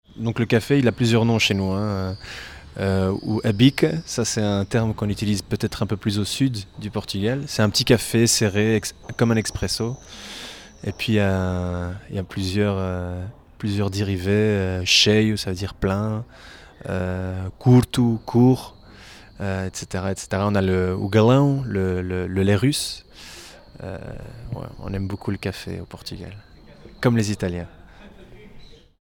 prononciation Bica.